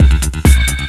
TECHNO125BPM 26.wav